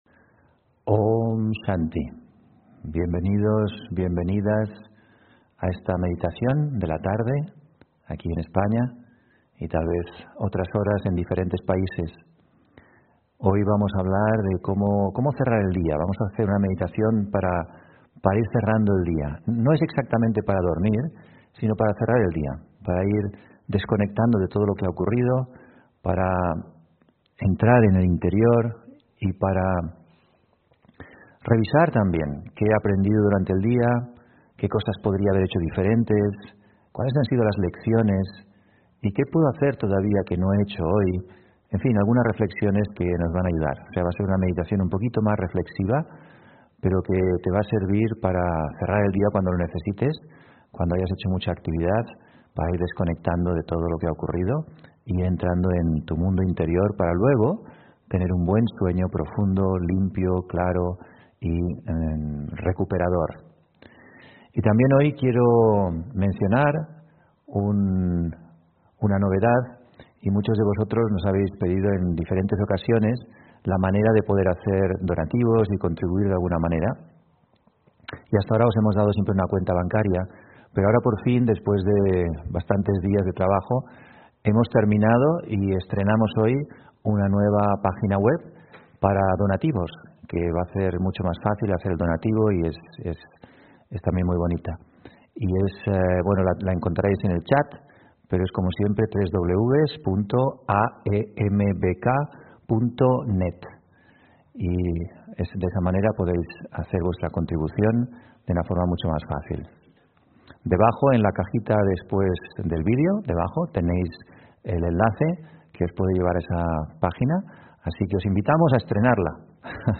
Meditación Raja Yoga: Meditación para cerrar el día (25 Septiembre 2020) On-line desde Madrid